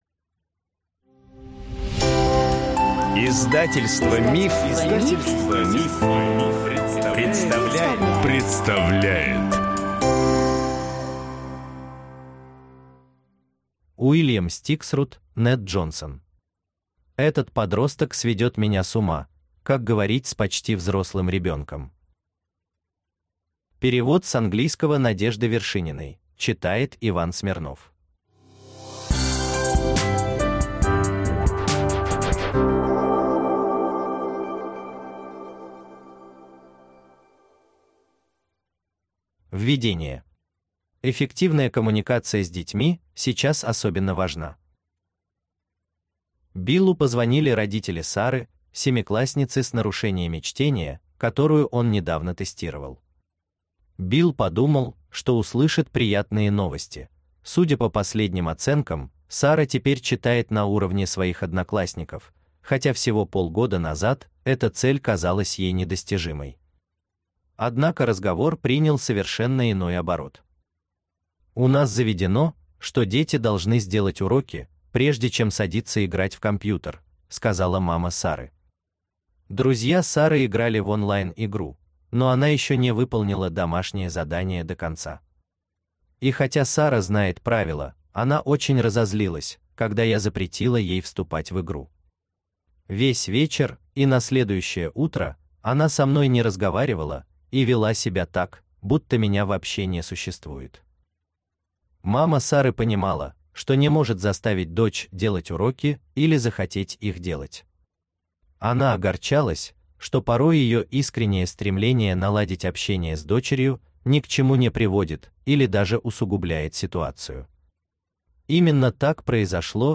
Аудиокнига Этот подросток сведет меня с ума! Как говорить с почти взрослым ребенком | Библиотека аудиокниг